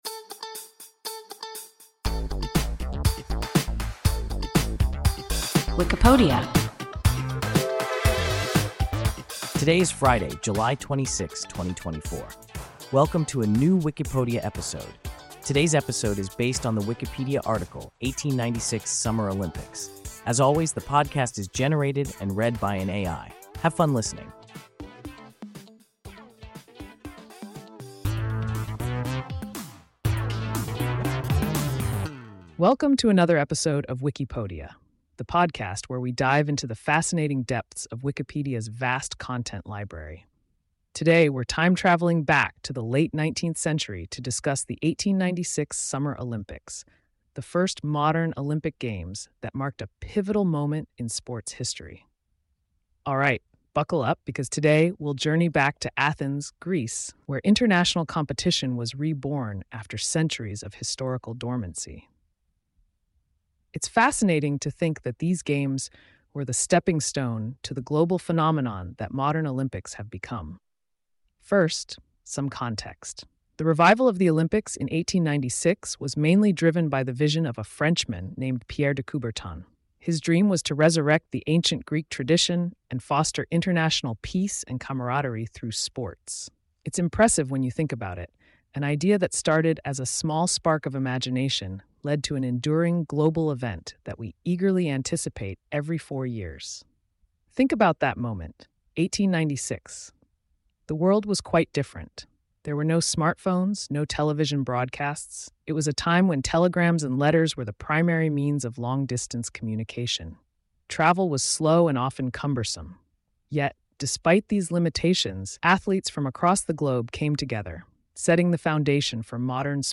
1896 Summer Olympics – WIKIPODIA – ein KI Podcast